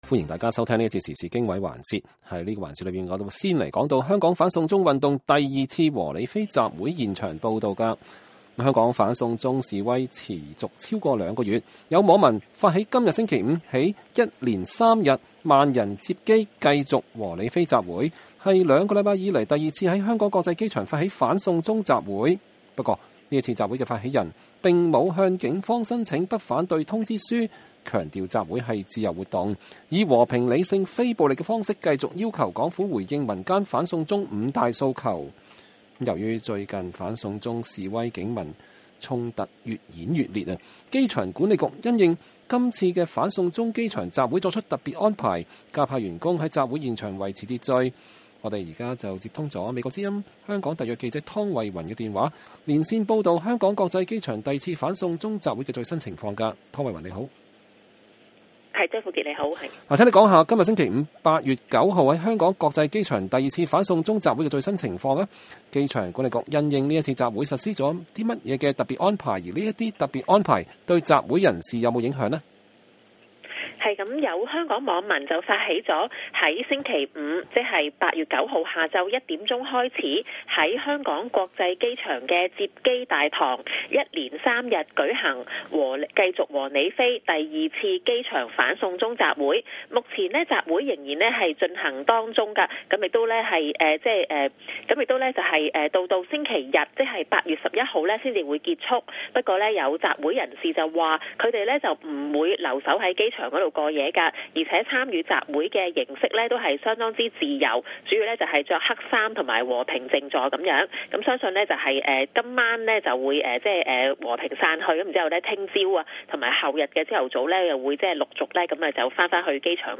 香港反送中運動 第二次“和你飛”集會現場報導